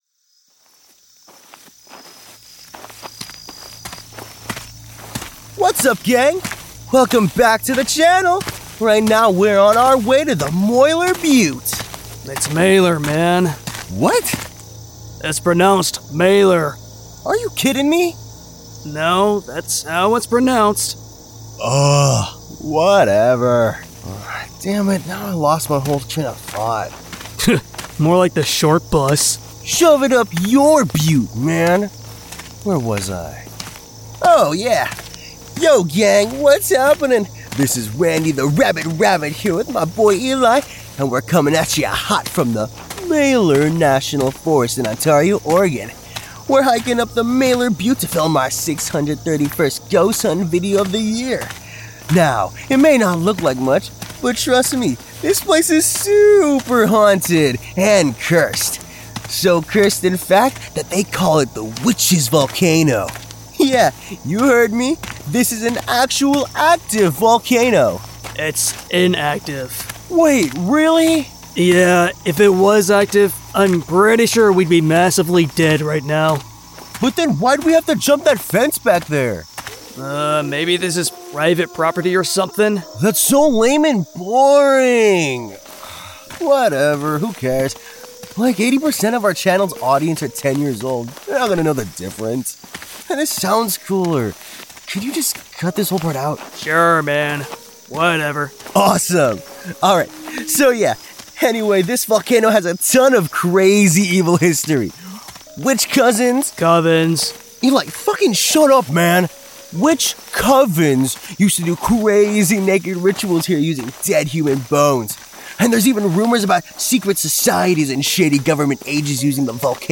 Tales from the Janitor: Chilling Urban Legends from Oregon | Horror Audio Drama